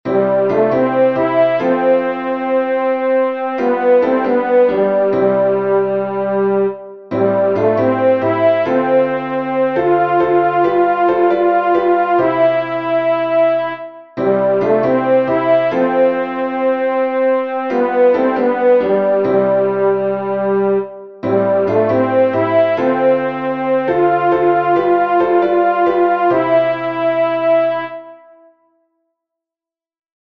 Tenor
open_wide_the_doors-tenor.mp3